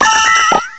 cry_not_cherrim.aif